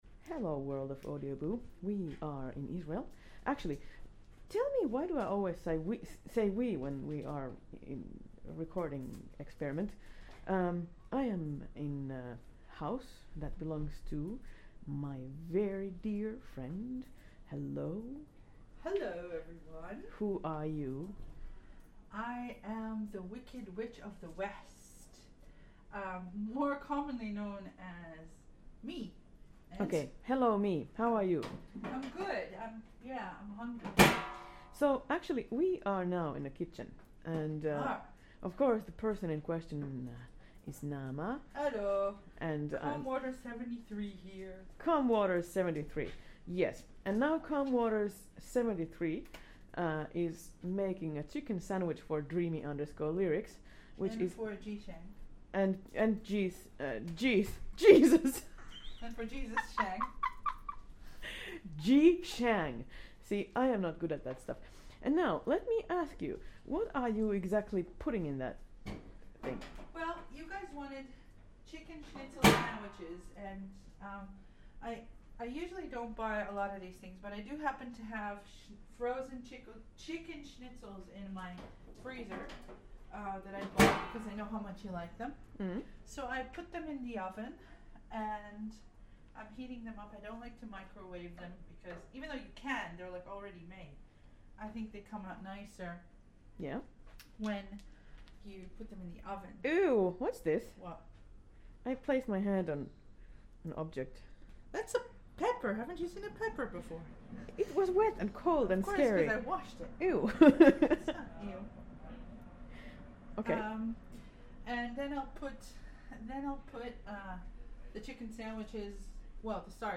Let's see what making a middle-eastern omelet sounds like recorded with Zoom h1 and SoundProfessionals Ms-bmc3's. Best listening experience with headphones.